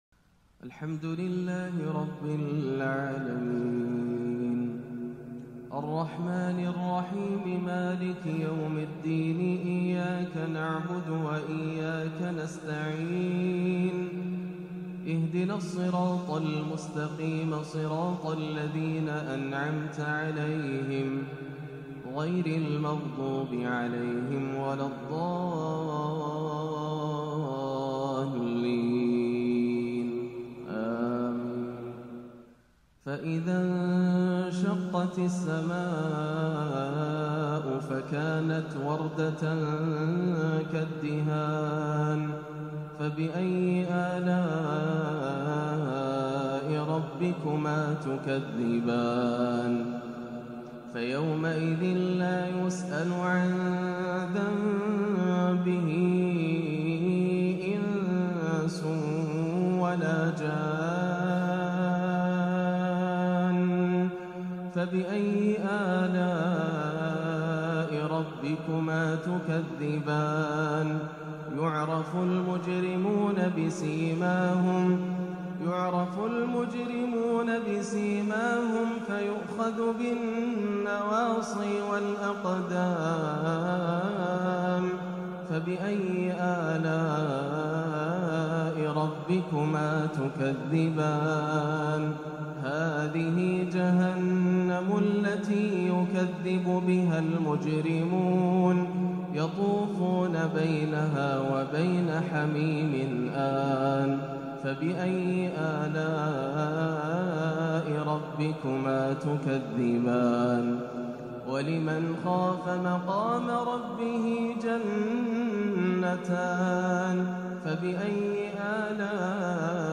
تحبير مميز عروس القرآن مليئ بالسكينة والطمأنينة بوصف لنعيم الجنة - الثلاثاء 1-2-1438 > عام 1438 > الفروض - تلاوات ياسر الدوسري